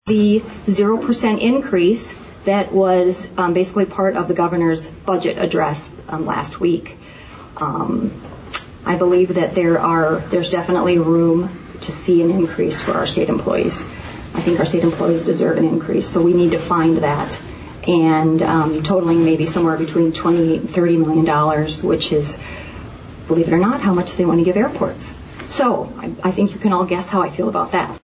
All three of the District 18 State Legislators addressed the Yankton School Board, last night.
State Representative, Julie Auch, talks about an issue that directly affects the schools in South Dakota…